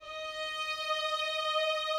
Added more instrument wavs
strings_063.wav